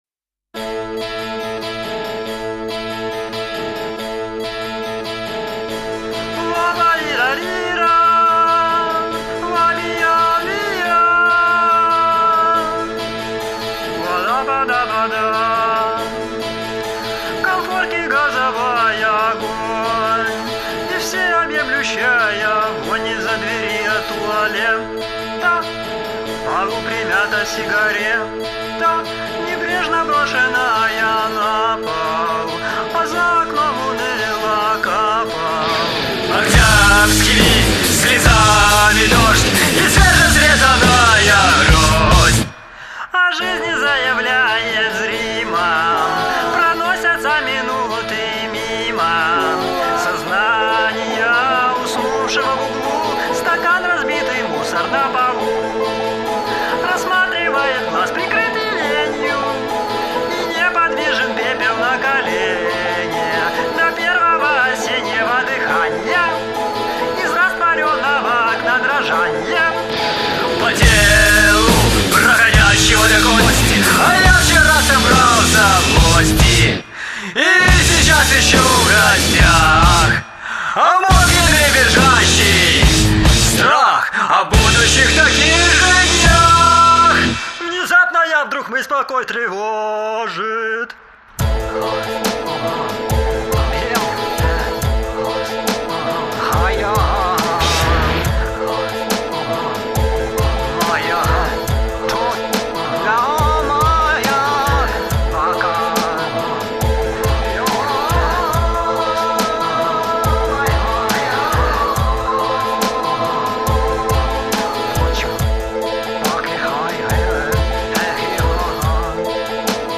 гитара, вокал
басс, хор
барабаны, хор